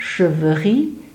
Siviriez (French: [sivʁije]; Arpitan: Severié [ʃəvəˈʁi]
Frp-greverin-Cheveri.ogg.mp3